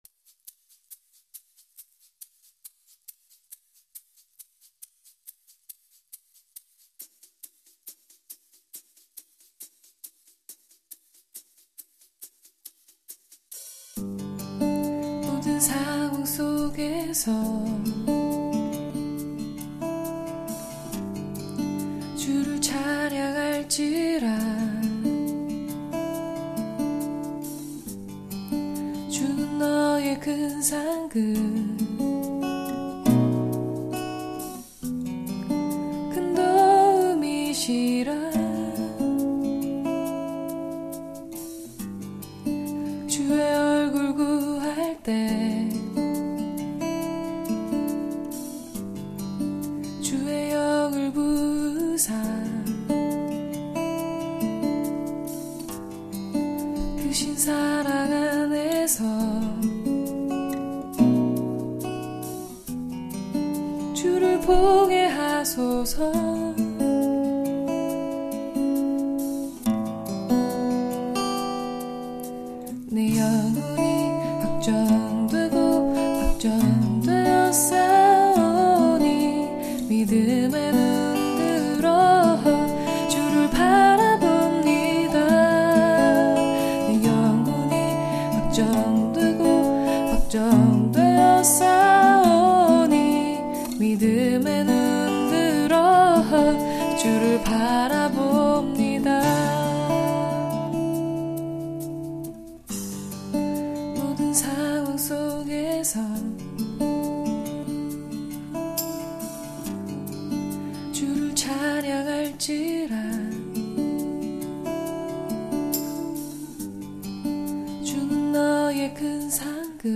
CCM 곡명 소개글